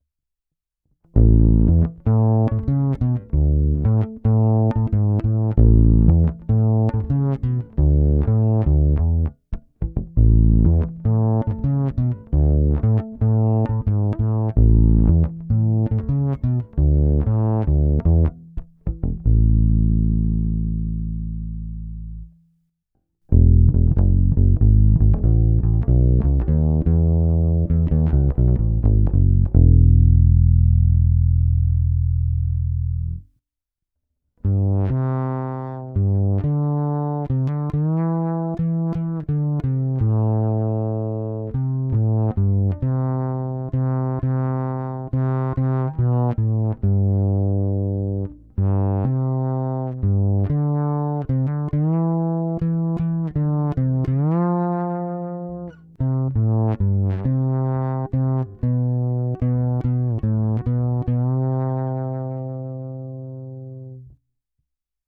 Je tučný, plný, krásně zpívá a mňouká.
Baskytara s nimi má i poměrně blízko svým charakterem ke kontrabasu, což můžete slyšet zvláště v prostřední část následující nahrávky. A když jsem zmínil dětskou velikost nástroje, doplnil jsem na konec ještě známý dětský pohádkový motiv, sorry jako.
Ukázka rovnou do zvukovky